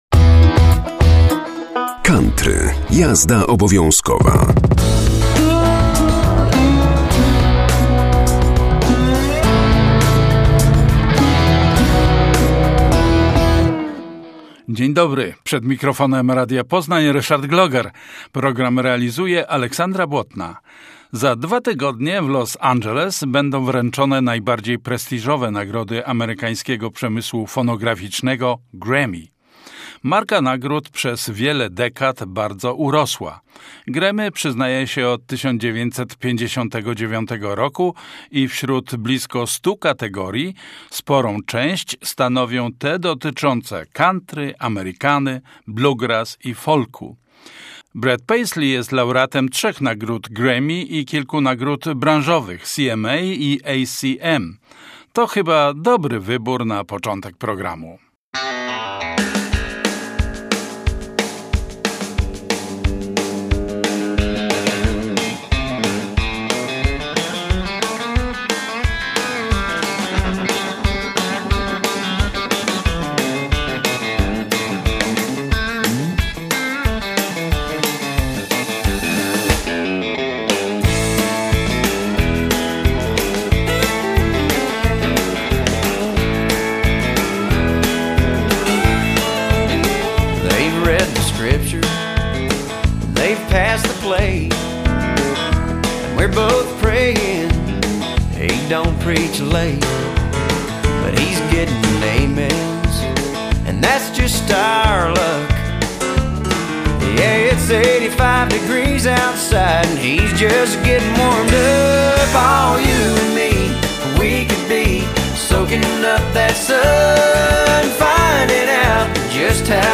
COUNTRY - JAZDA OBOWIĄZKOWA 18.01.2026